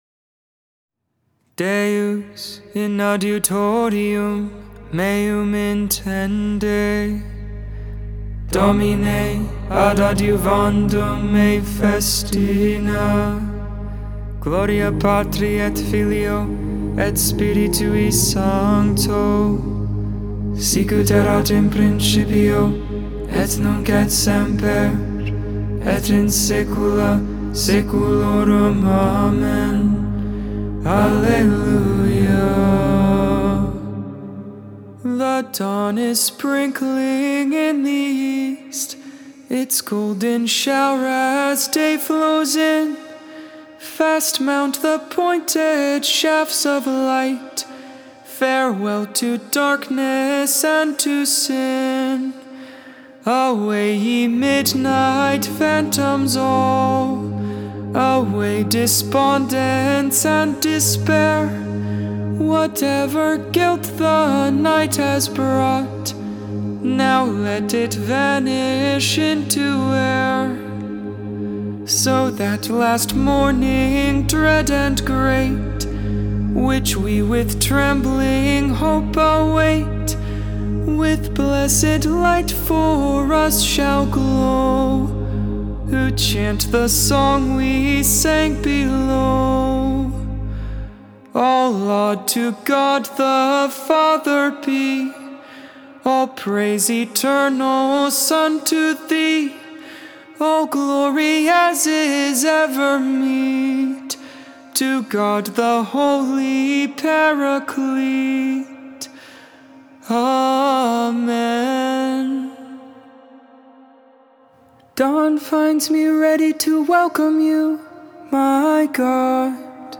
The Liturgy of the Hours: Sing the Hours 9.24.22 Lauds, Saturday Morning Prayer Sep 23 2022 | 00:14:14 1x 00:00 / 00:14:14 Subscribe Share Spotify RSS Feed Share Link Embed